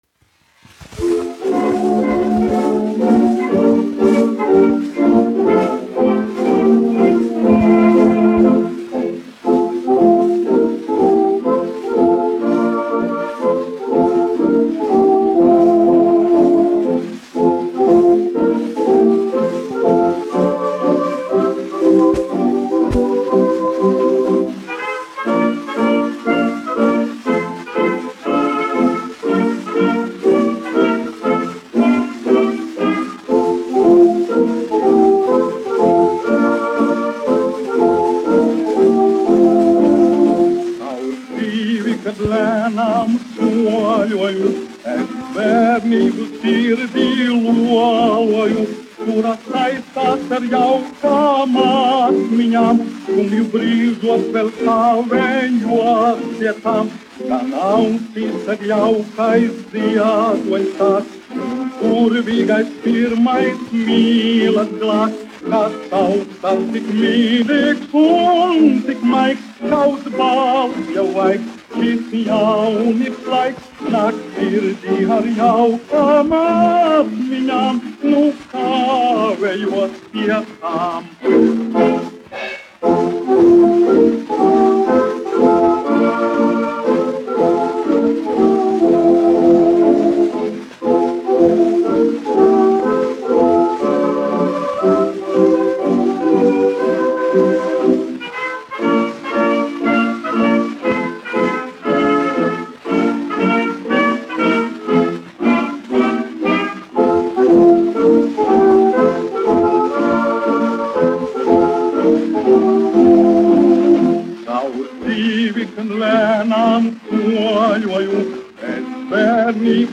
1 skpl. : analogs, 78 apgr/min, mono ; 25 cm
Marši
Populārā mūzika
Skaņuplate